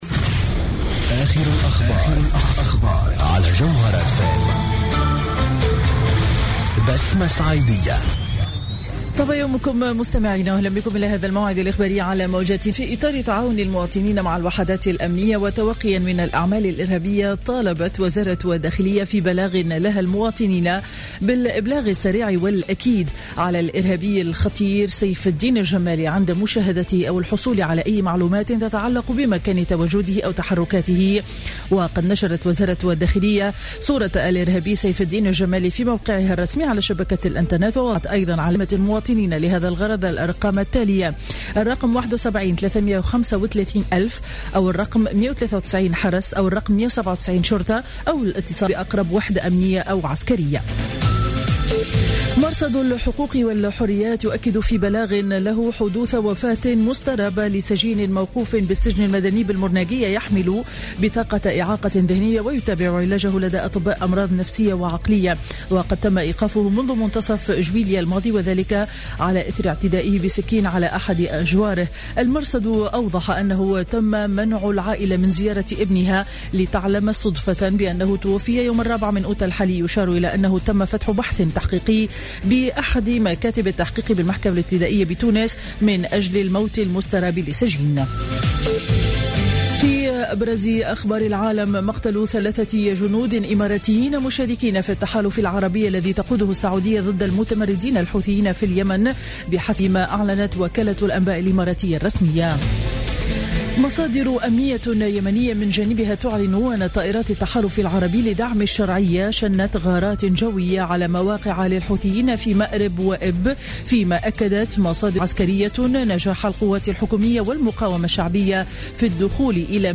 نشرة أخبار السابعة صباحا ليوم الأحد 09 أوت 2015